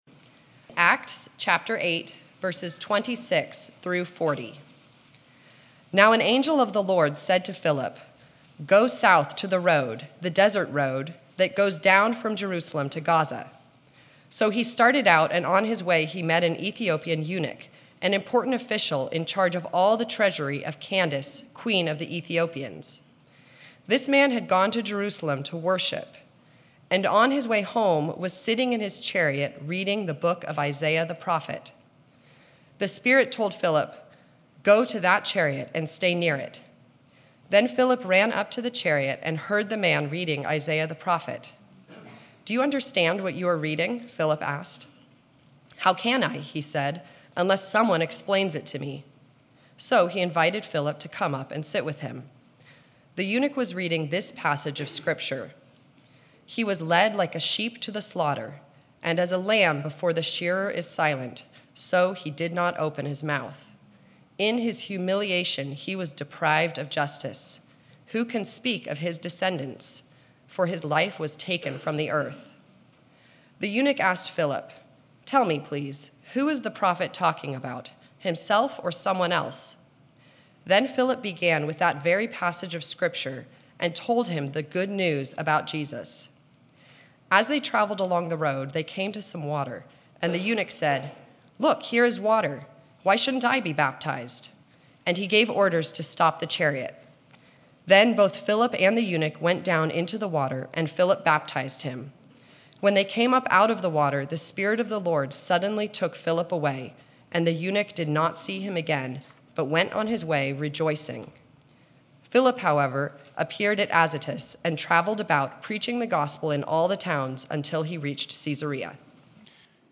Sermons | Grace Valley Christian Center